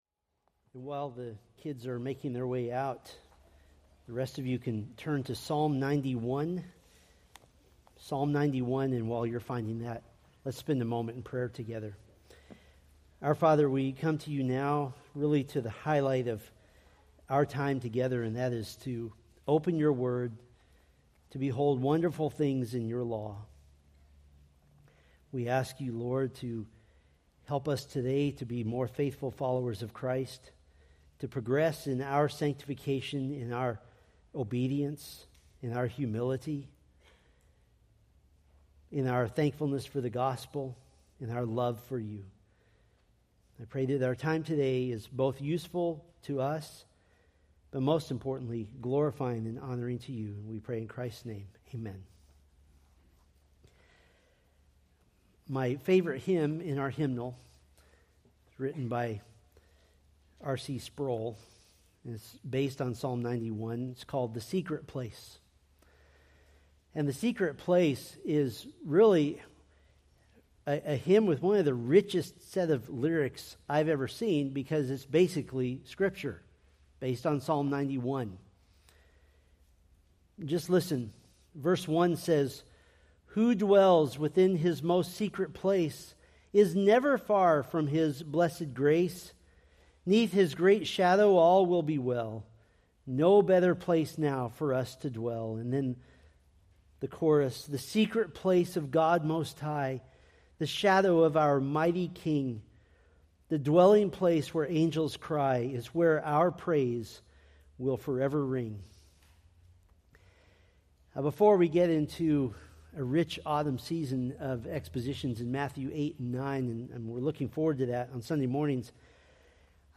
Preached August 25, 2024 from Psalm 91